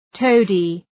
Προφορά
{‘təʋdı}